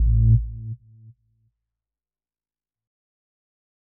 Звуки выключения
Звук последнего вздоха девайса